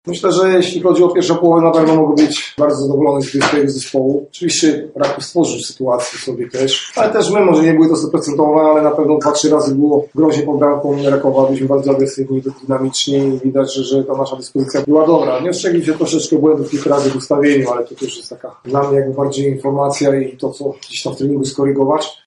mówił na konferencji prasowej